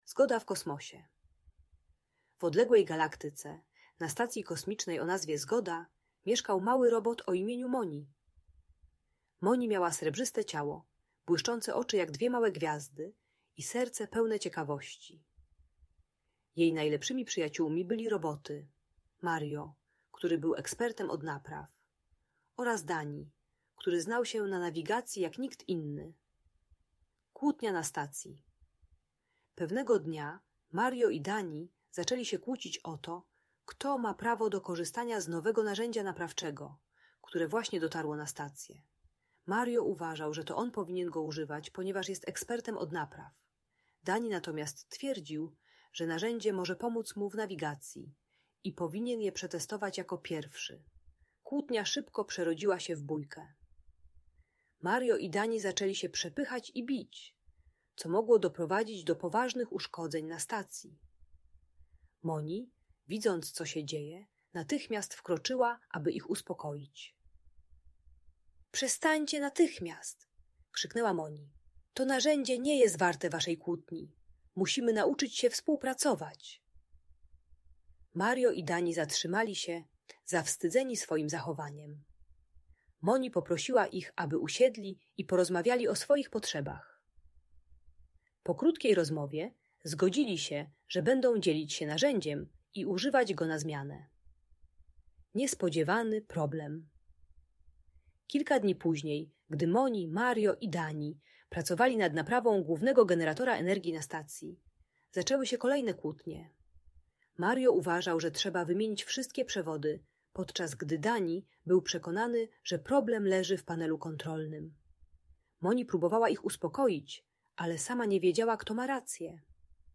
Zgoda w Kosmosie: Nauka o współpracy - story - Audiobajka dla dzieci